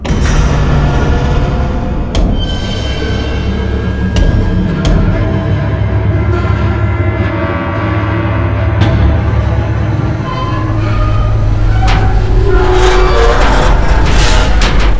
Звук коллапса гравитационной аномалии